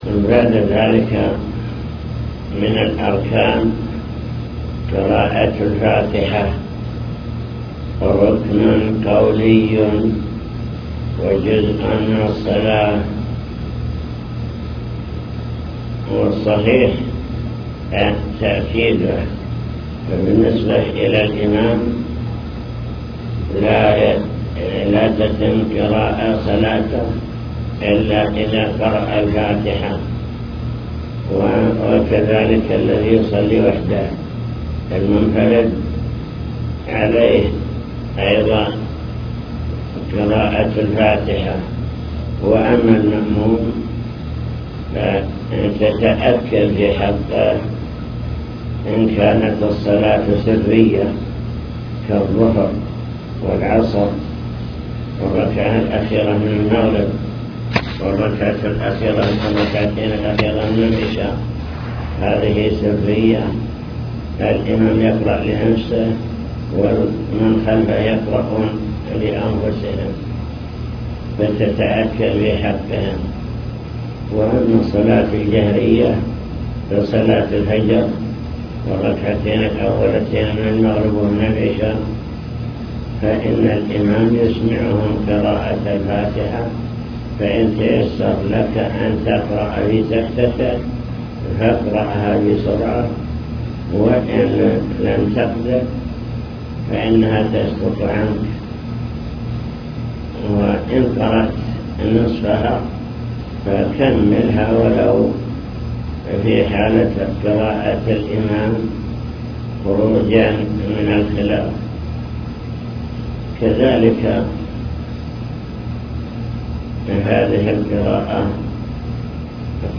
المكتبة الصوتية  تسجيلات - لقاءات  حول أركان الصلاة (لقاء مفتوح) من أركان الصلاة: قراءة الفاتحة